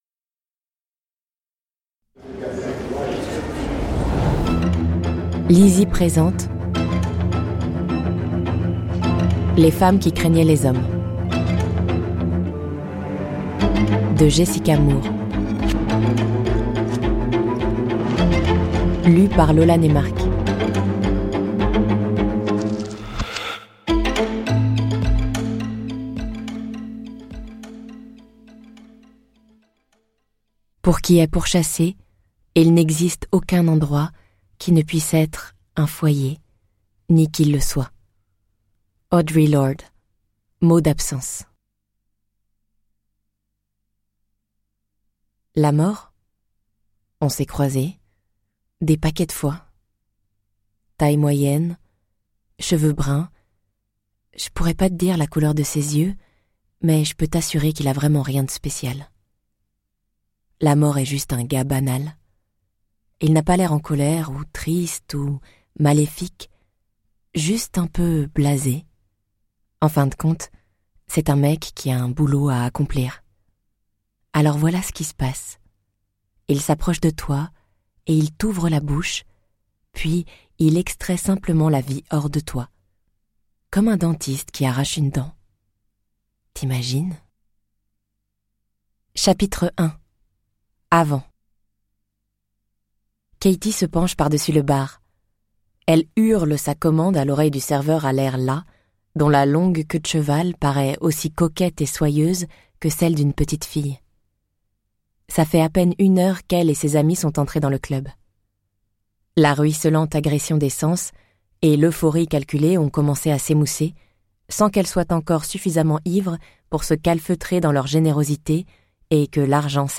Click for an excerpt - Les Femmes qui craignaient les hommes de Jessica Moor